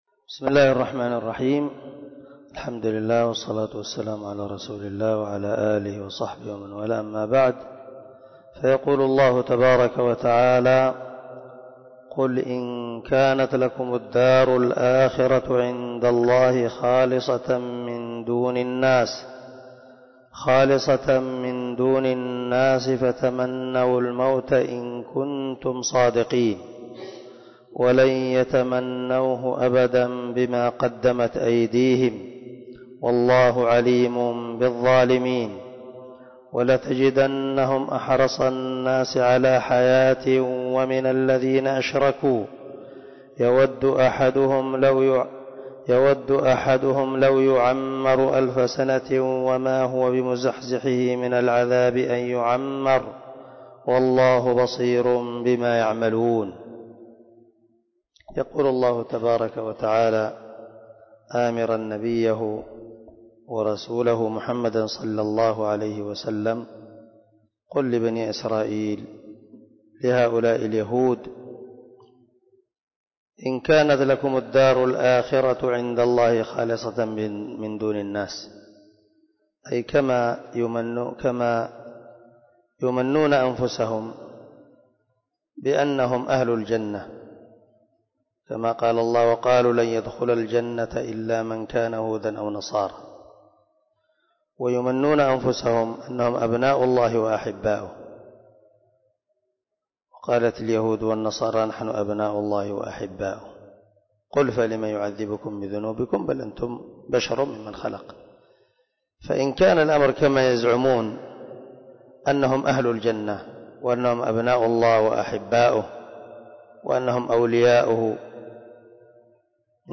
040الدرس 30 تفسير آية ( 94 – 98 ) من سورة البقرة من تفسير القران الكريم مع قراءة لتفسير السعدي